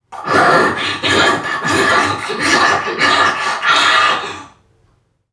NPC_Creatures_Vocalisations_Robothead [72].wav